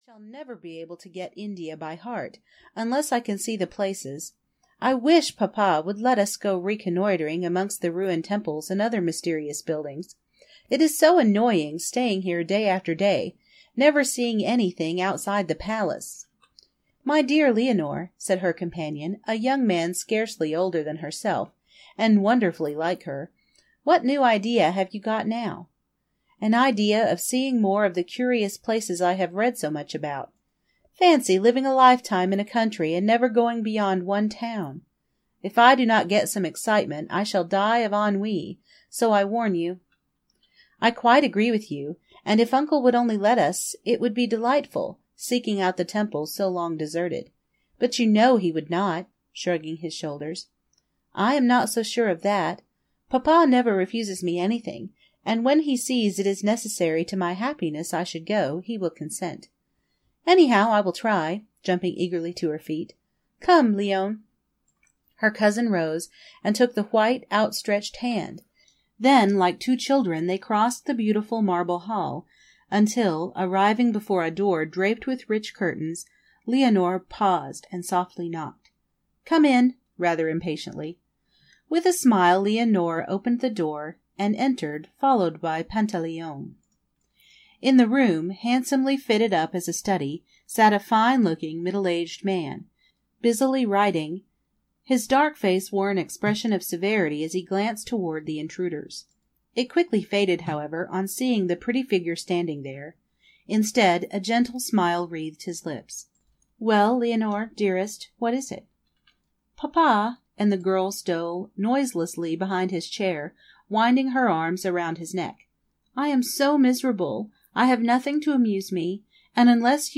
Won by Crime (EN) audiokniha
Ukázka z knihy